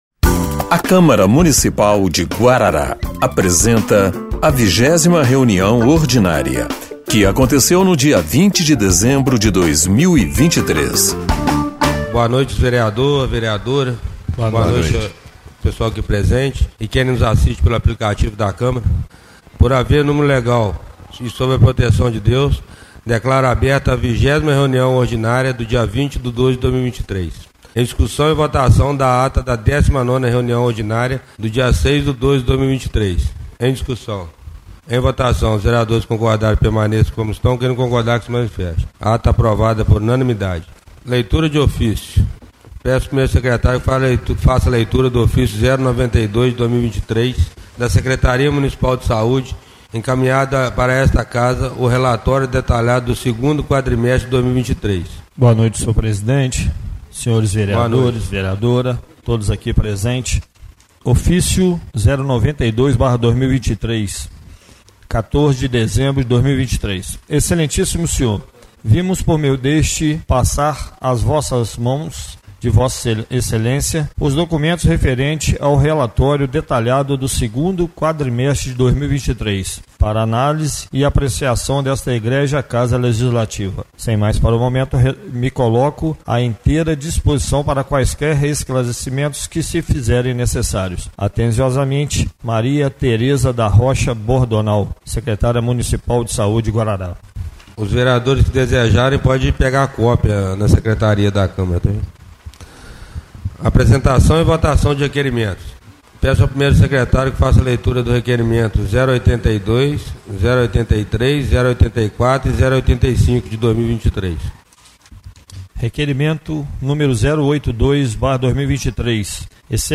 20ª Reunião Ordinária de 20/12/2023